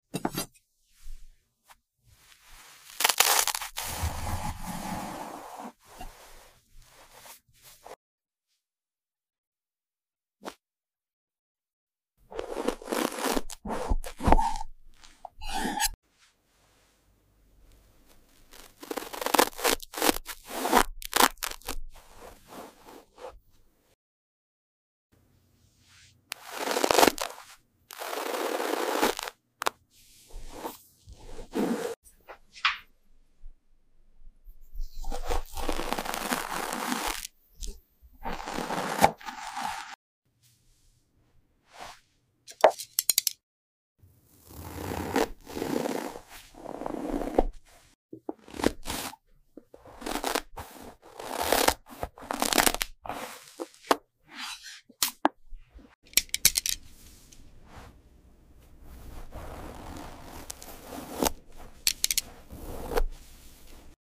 Soft, squishy cakes and dreamy textures that melt into pure sound. No talking. Just the soothing crackle, press and slice of ultra-satisfying dessert cuts. Perfect for relaxing, tingles, and dessert lovers 🍩✨ Turn your volume up 🎧 and comment which plushy dessert should come next!